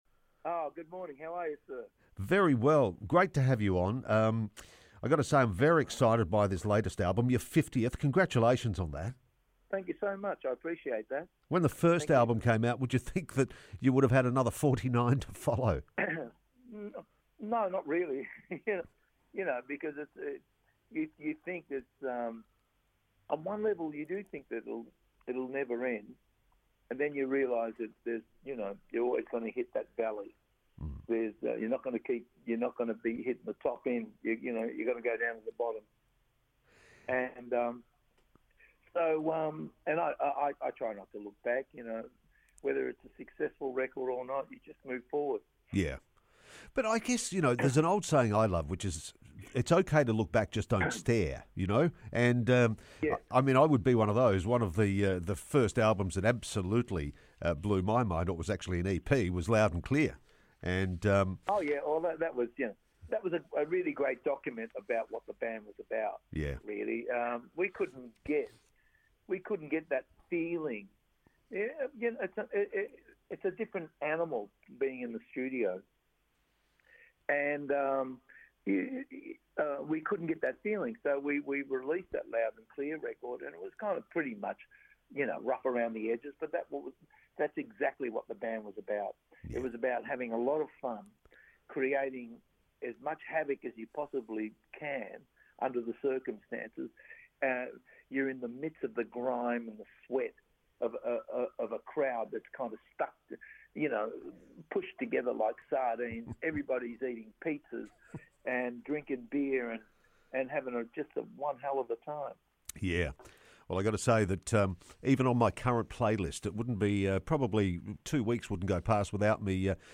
Joe Camilleri - Full interview